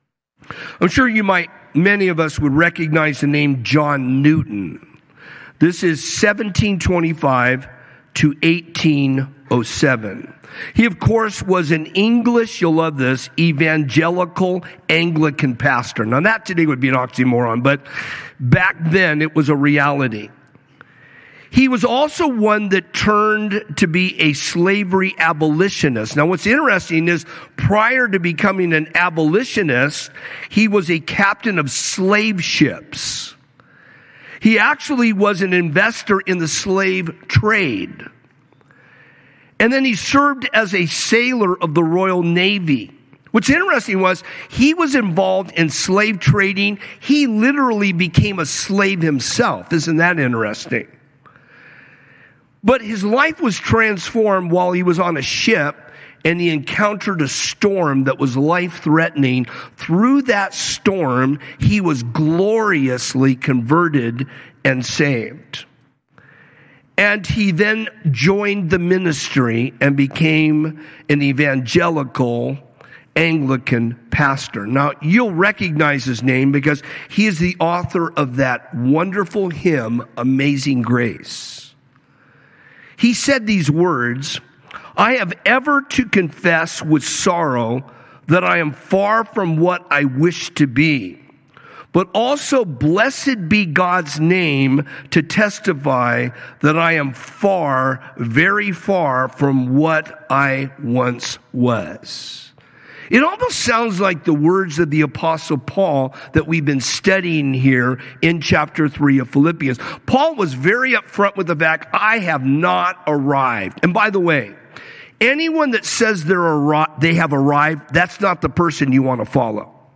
A message from the series "Sunday Morning - 10:30."